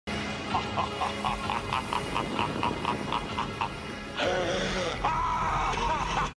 Freddy Krueger laugh
freddy-krueger-laugh.mp3